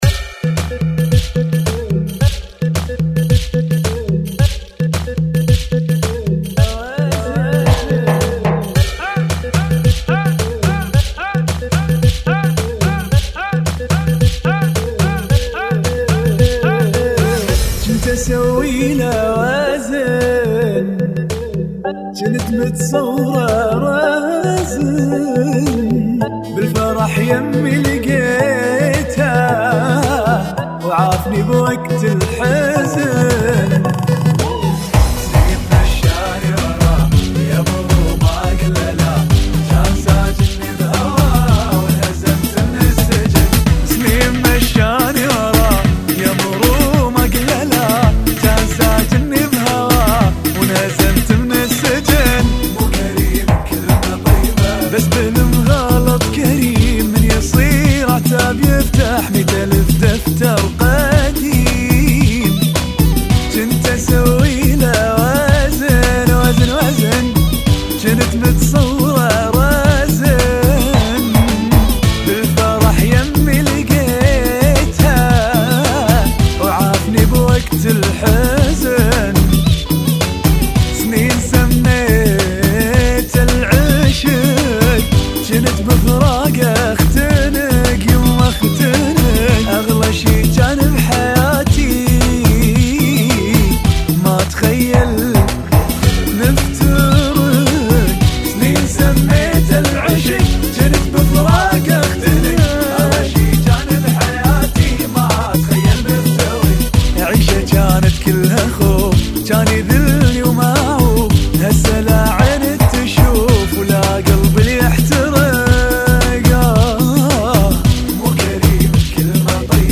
Funky [ 110 Bpm ]